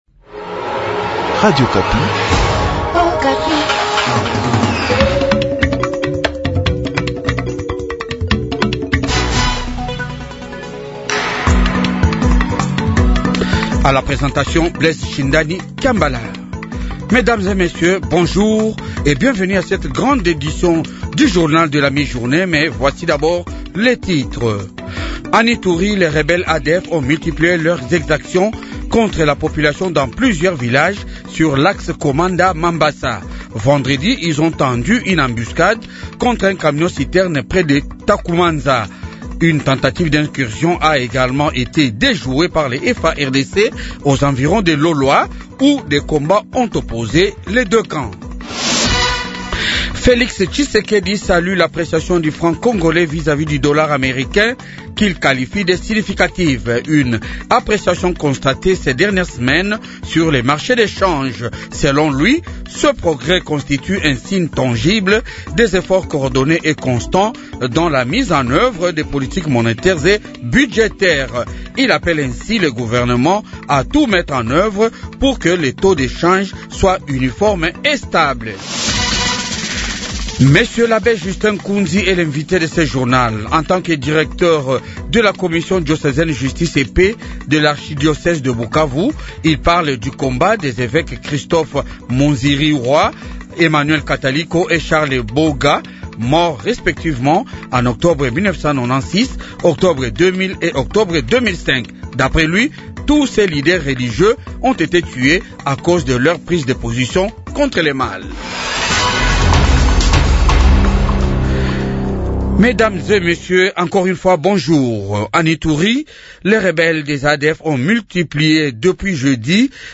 Journal Francais midi